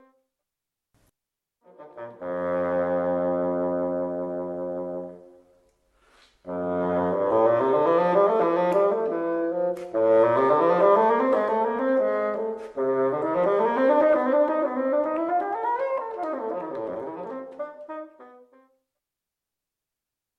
fagot
O son deste instrumento é claramente máis grave, debido a que o seu tamaño tamén é maior.
fagot.mp3